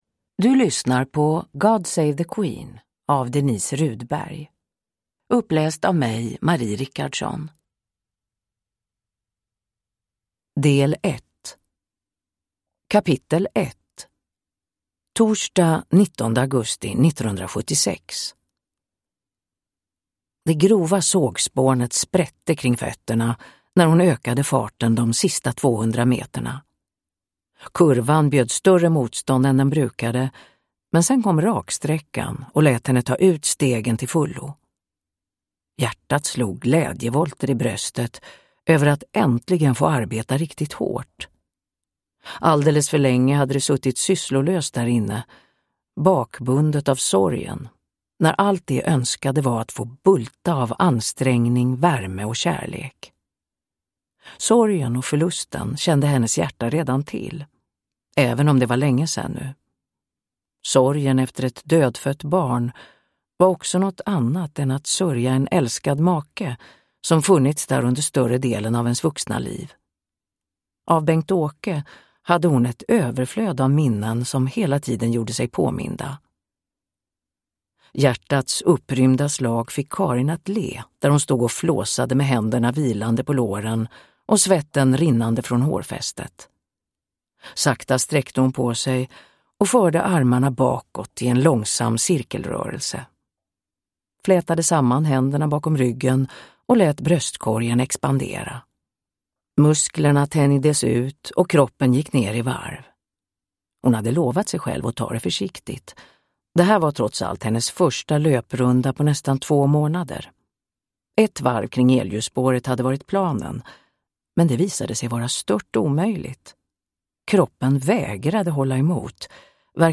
God Save the Queen – Ljudbok
Uppläsare: Marie Richardson